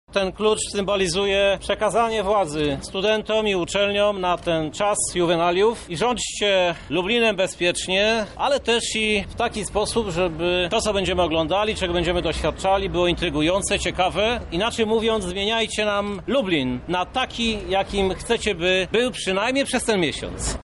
Zgodnie z tradycją korowód rozpoczął się symbolicznym przejęciem klucza do miasta przez studentów. Przekazał go prezydent Krzysztof Żuk.